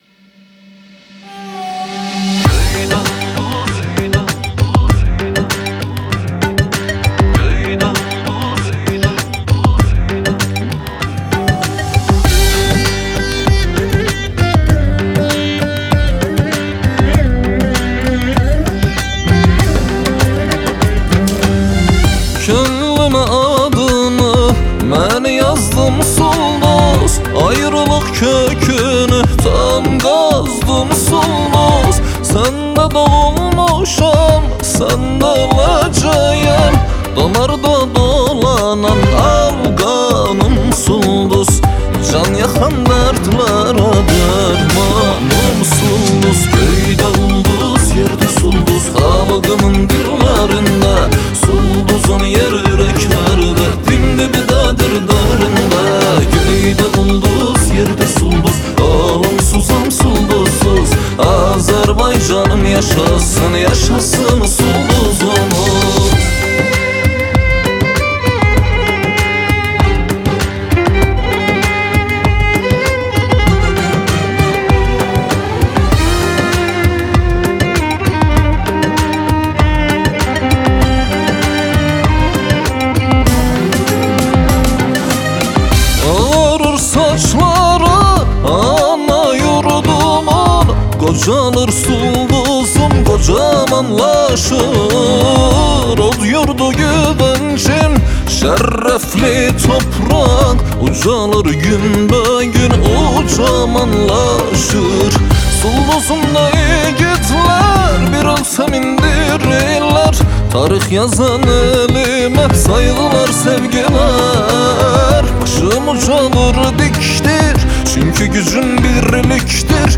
آهنگ آذربایجانی آهنگ غمگین آذربایجانی آهنگ هیت آذربایجانی